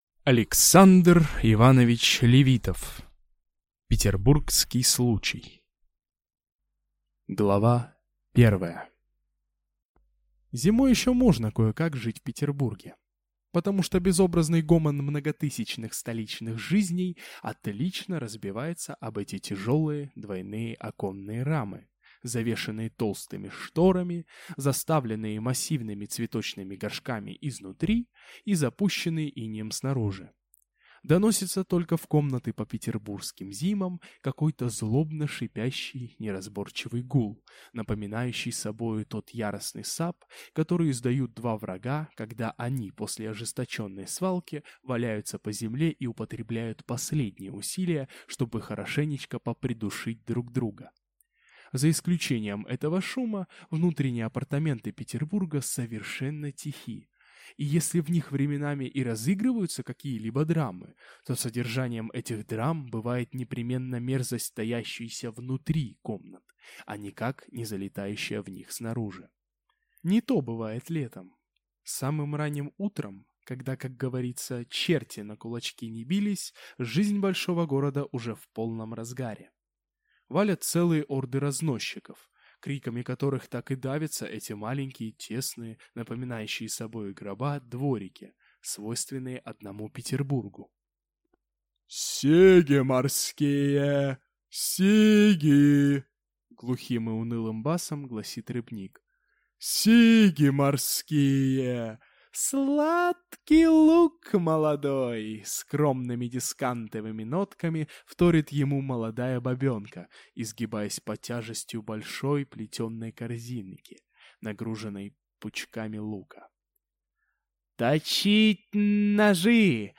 Аудиокнига Петербургский случай | Библиотека аудиокниг